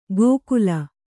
♪ gōkula